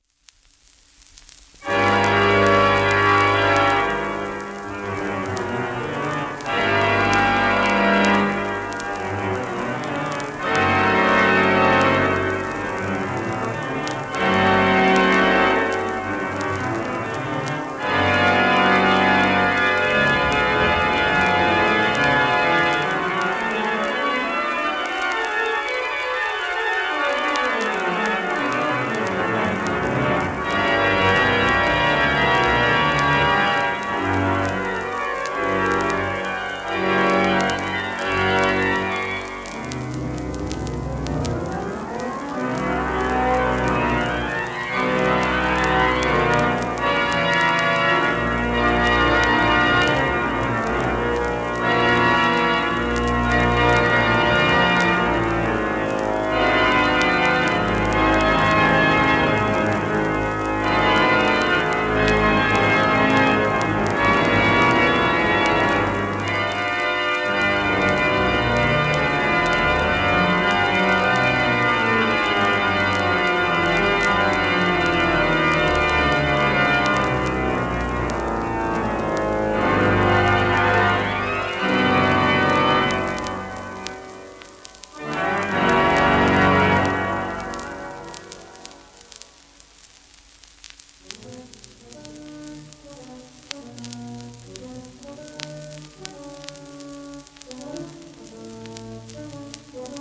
1929年、ハンブルグ、聖ミカエル教会のオルガン録音